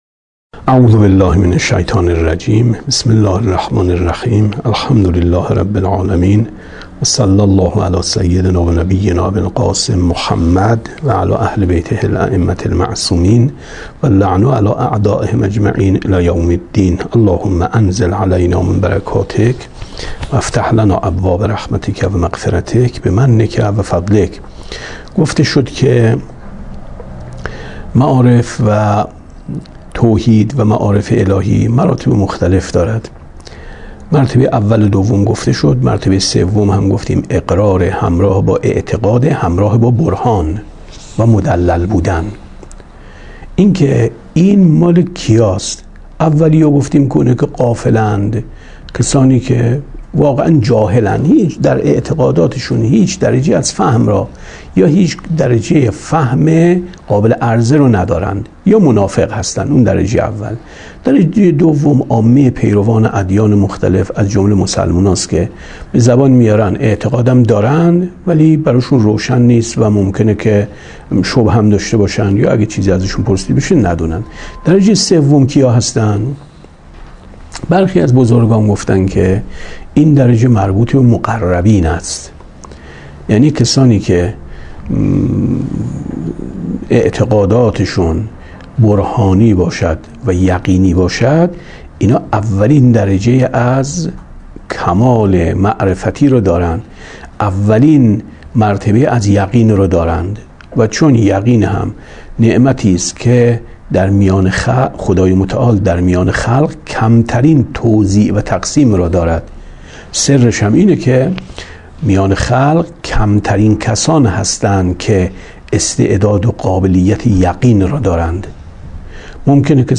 کتاب توحید ـ درس 30 ـ 13/ 8/ 95